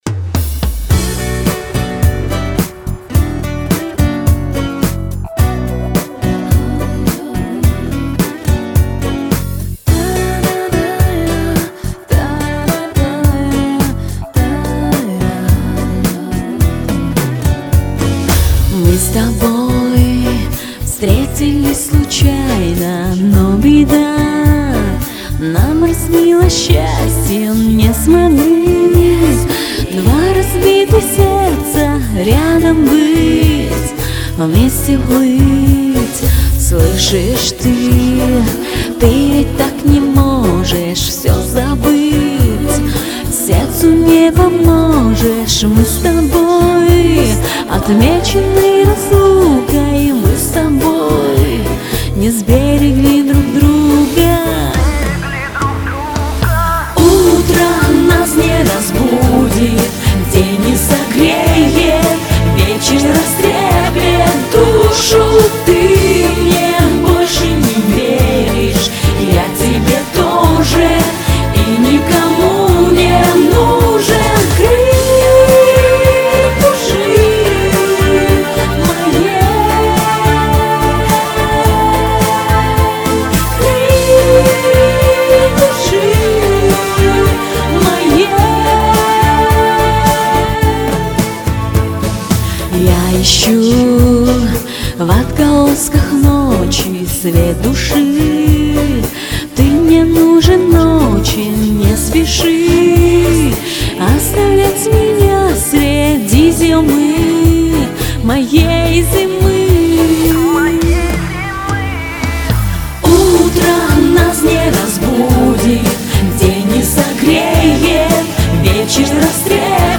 Это именно тот случай, когда не хватает эмоций и драйва...